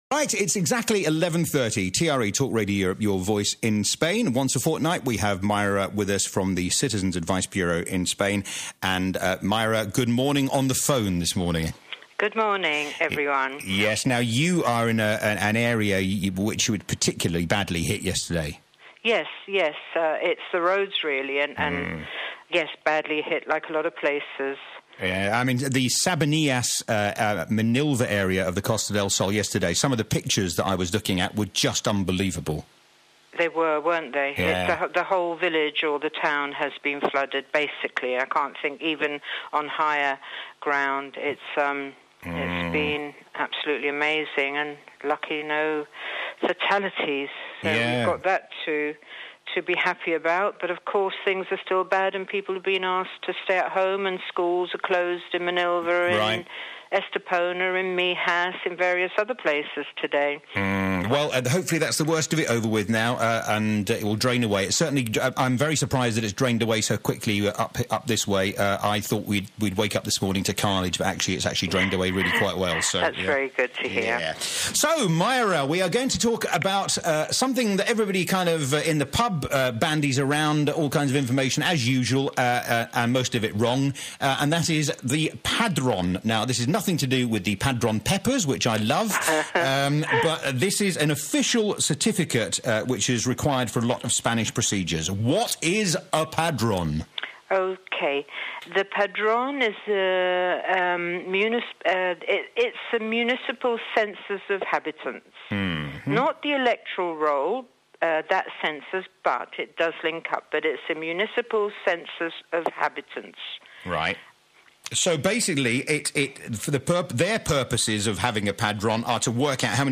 Registering on the Padrón (Census) Radio Interview